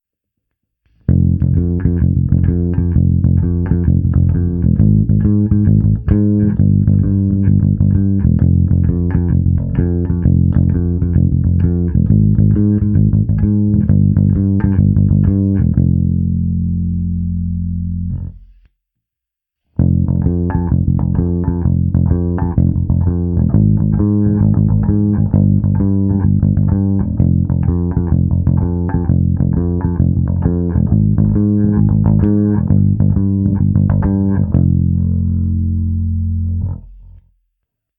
Předchozí ukázku jsem prohnal softwarem AmpliTube se zapnutou simulací basového aparátu snímaného mikrofony pro lepší představu "živého" zvuku.